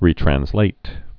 (rētrăns-lāt, -trănz-, rē-trănslāt, -trănz-)